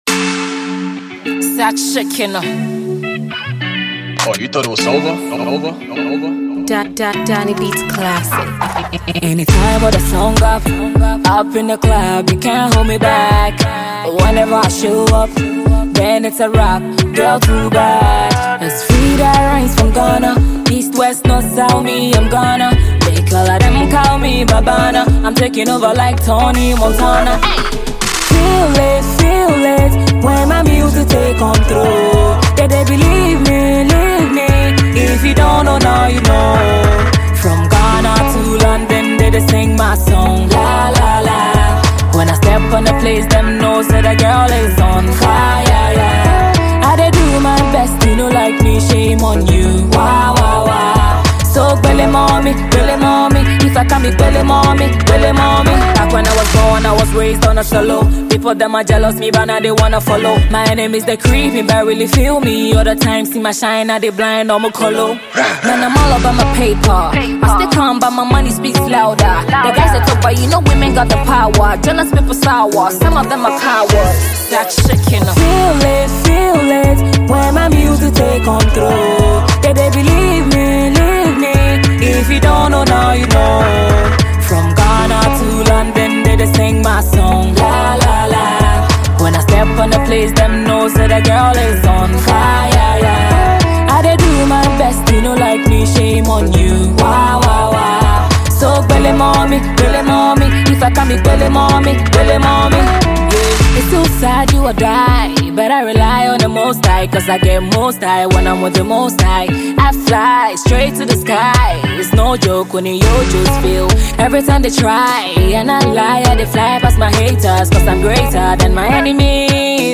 Ghanaian female rapper